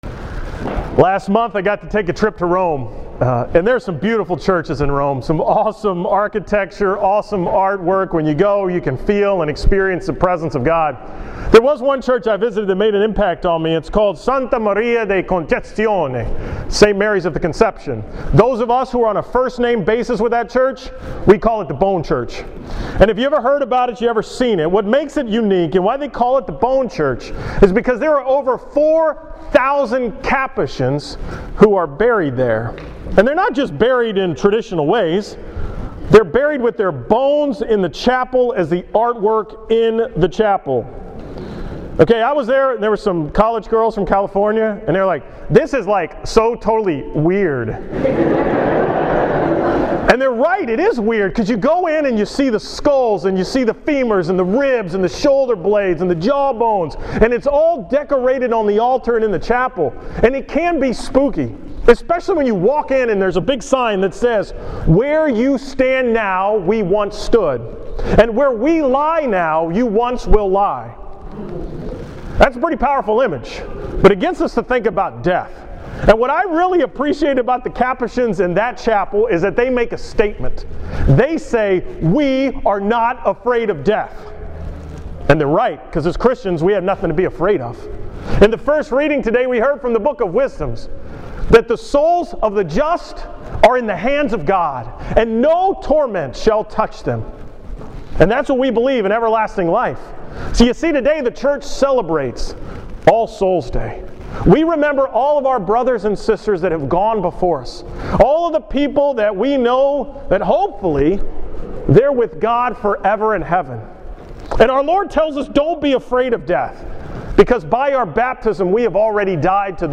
From the All Soul’s Day Mass on Sunday, November 2nd
Holy Rosary Church in Galveston, TX